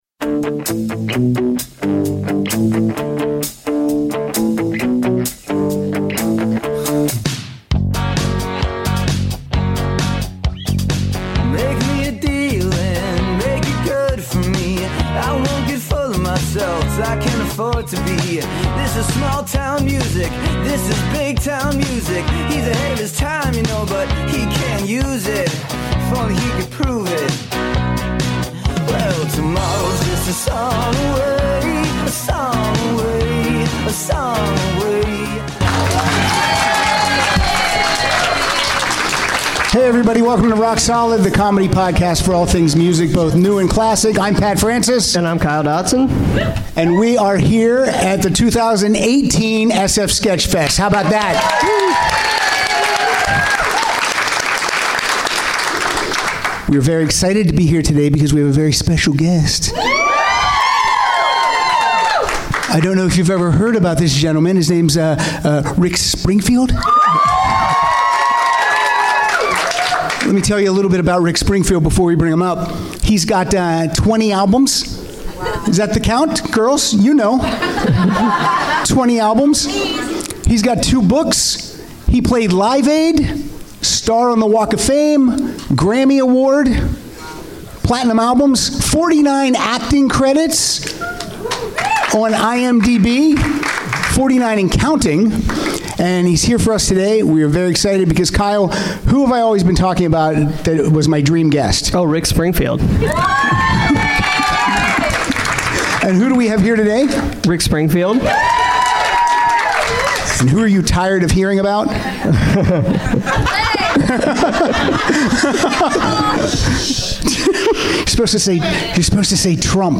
Recorded LIVE on Saturday January 20, 2018 at Cobb's Comedy Club with Guest Rick Springfield.